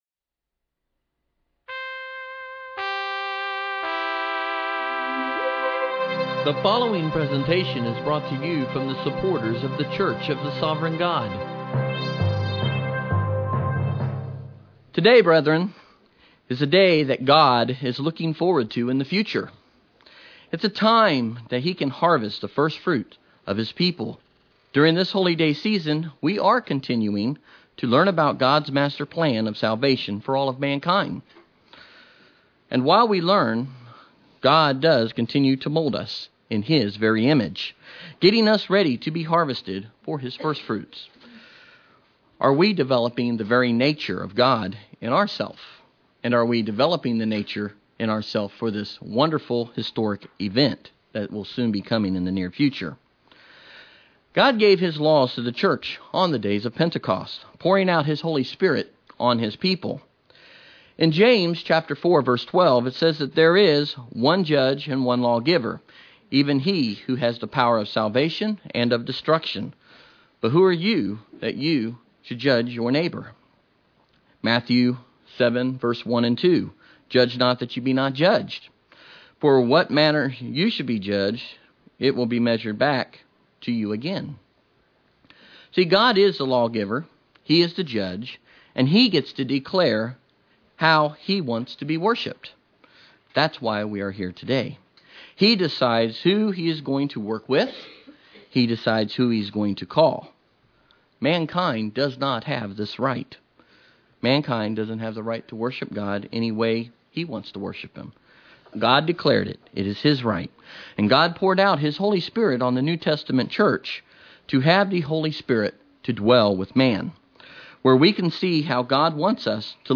Audio sermons about God's annual Holy Days found in Leviticus 23